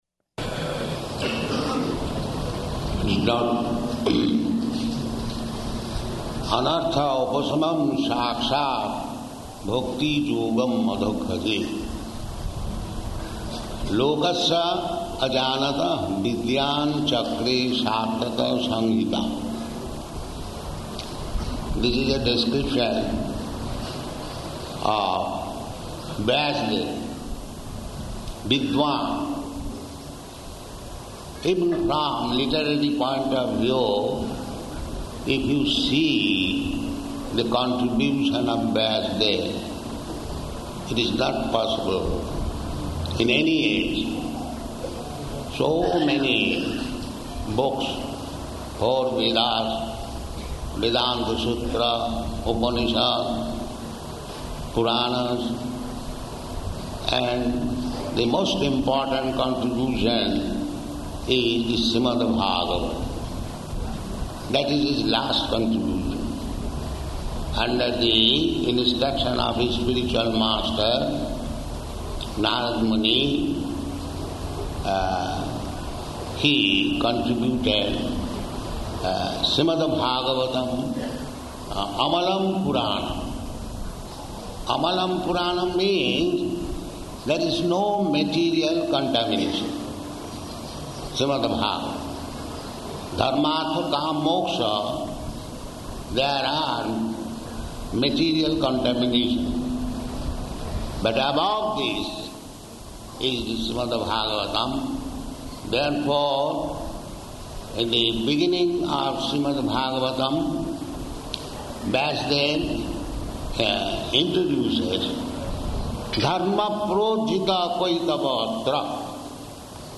Location: Hyderabad